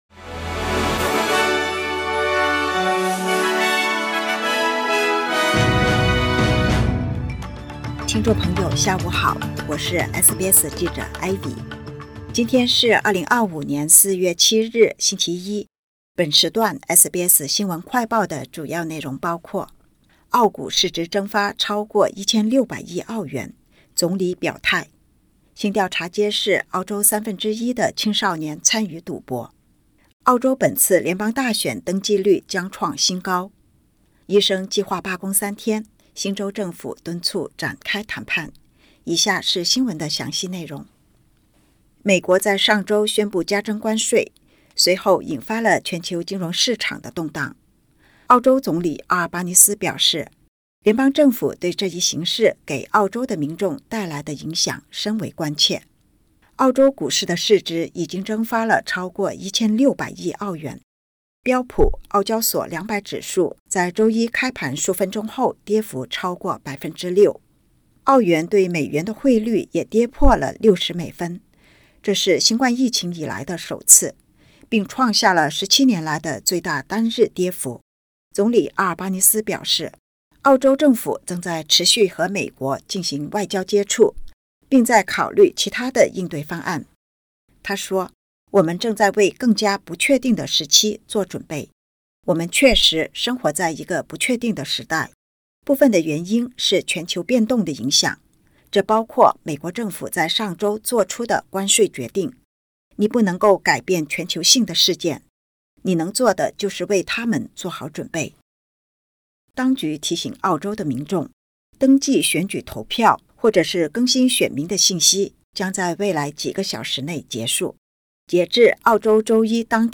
【SBS新闻快报】澳洲股市蒸发逾1600亿澳元 总理：做好准备